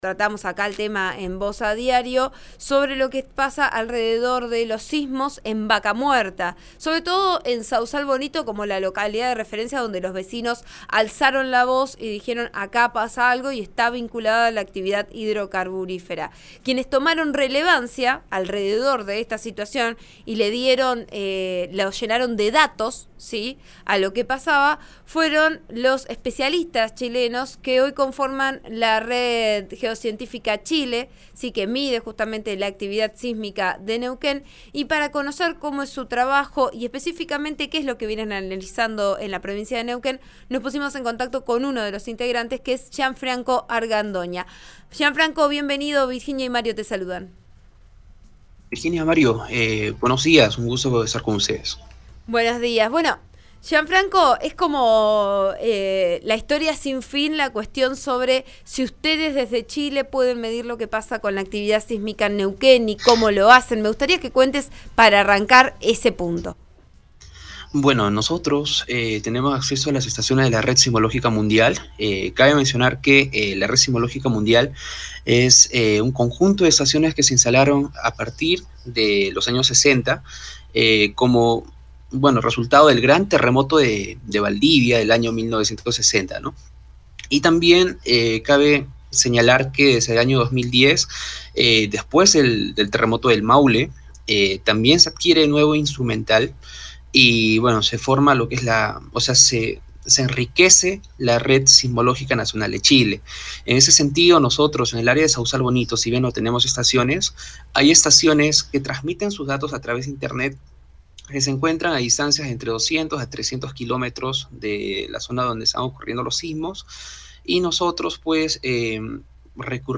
El de mayor magnitud ocurrió a las 9.23 y fue de 2.4° en la escala de Richter. En RN Radio, uno de los integrantes de la Red Geocientífica Chile explicó lo que viene ocurriendo.